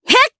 Cecilia-Vox_Attack1_jp.wav